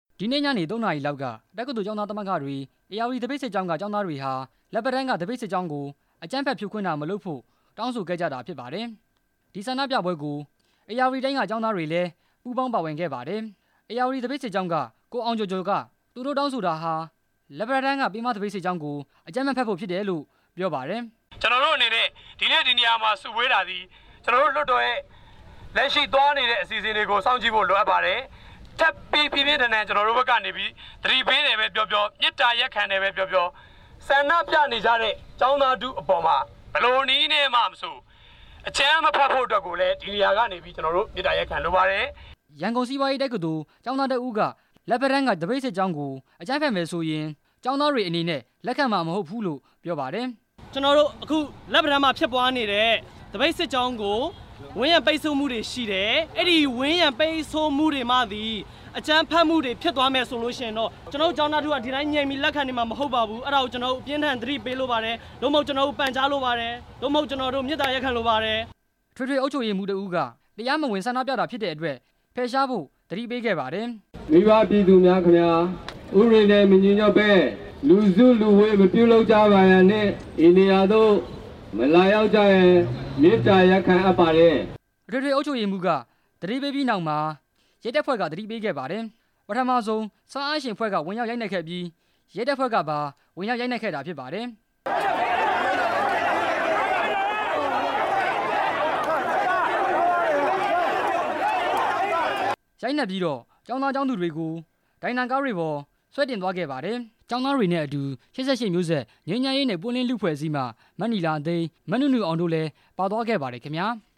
အကြမ်းဖက်ဖြိုခွင်းမှု တင်ပြချက်